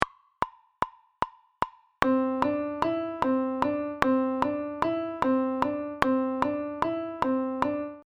Subdivisión métrica del compás en 3 + 2
Ejemplo de compás de 5x8 con la medida de 3+2.
COMPAS-5x8-1.mp3